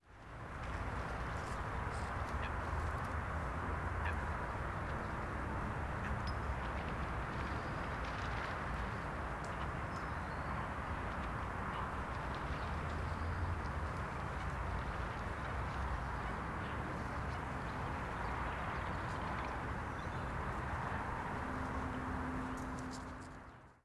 Patos en una laguna de un parque